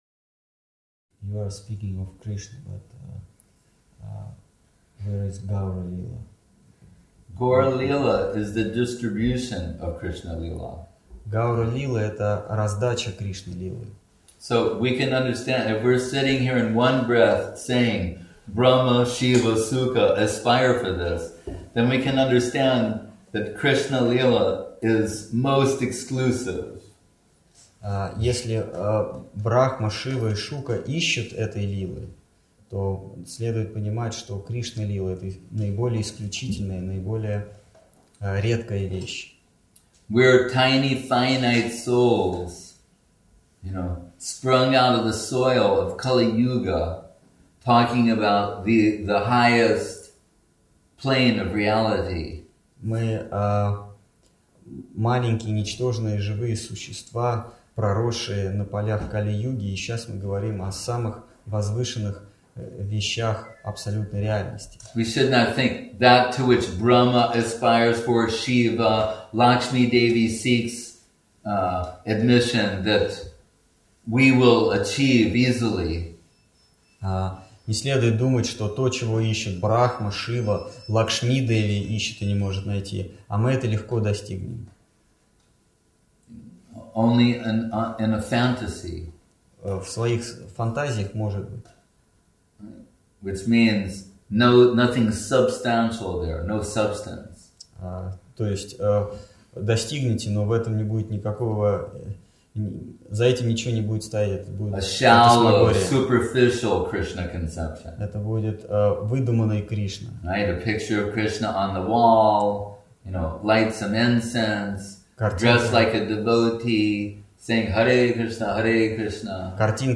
Place: Centre «Sri Chaitanya Saraswati» Moscow